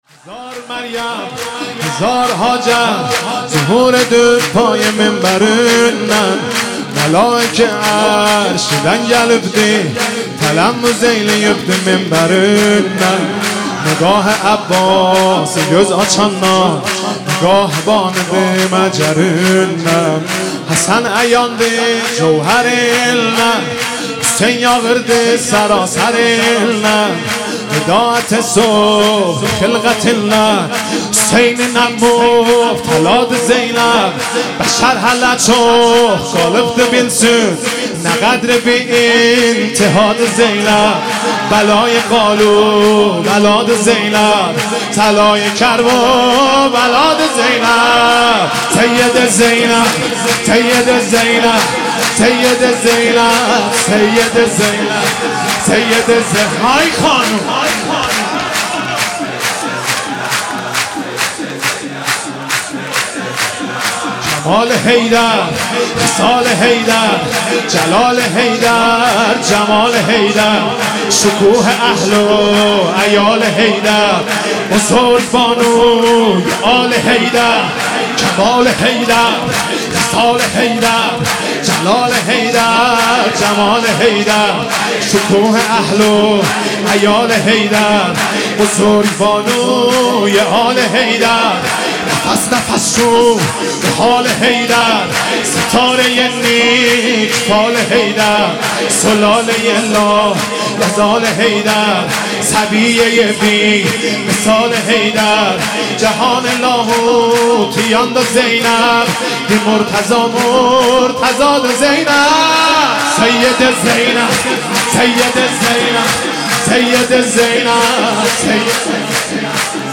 صوت/ مولودی خوانی حاج مهدی رسولی